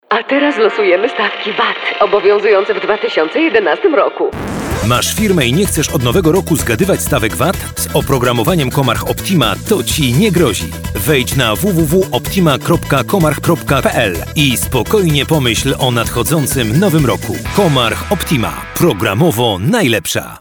Spot radiowy
Spot_radiowy.mp3